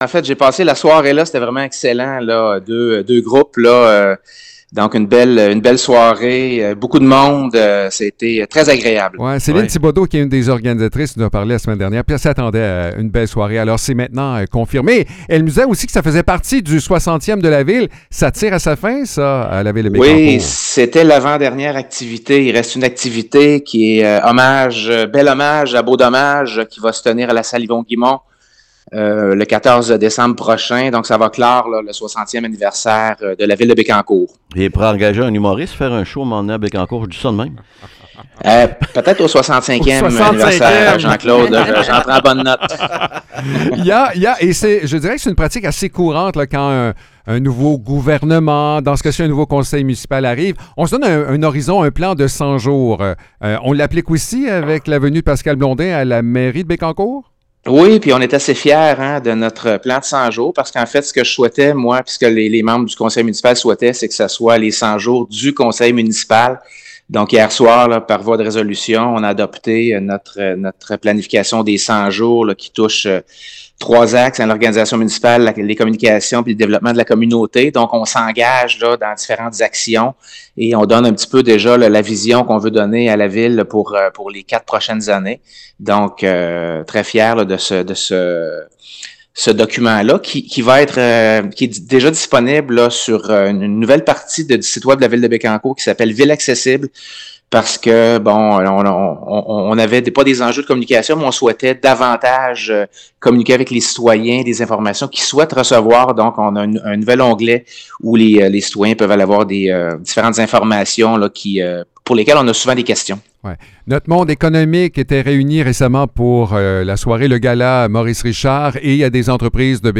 Pascal Blondin, maire de Bécancour, nous parle des dernières festivités entourant le 60e anniversaire de la ville. Il revient également sur le gala Maurice-Richard, un événement qui a mis en lumière le talent et la réussite des entrepreneurs d’ici.